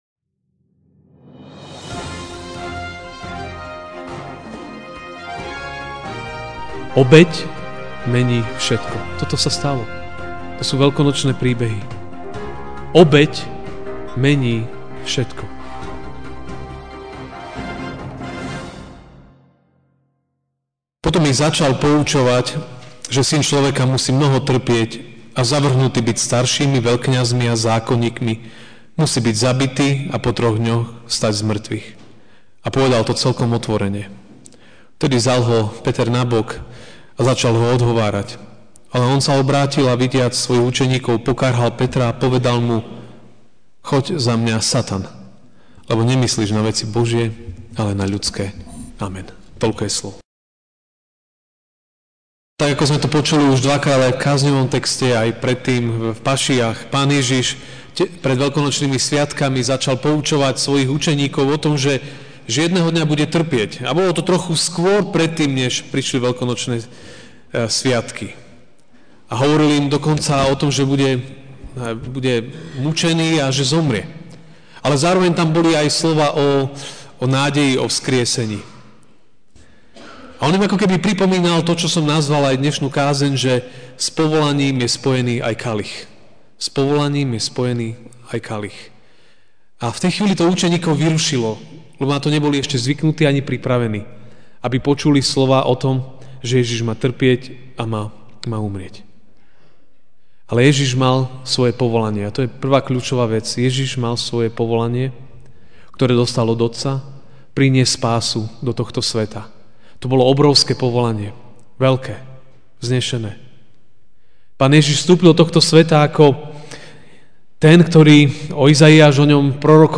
Ranná kázeň: Povolanie & kalich (Mk. 8, 31-33) Potom ich začal poučovať, že Syn človeka musí mnoho trpieť a zavrhnutý byť staršími, veľkňazmi a zákonníkmi, musí byť zabitý a po troch dňoch vstať z mŕtvych.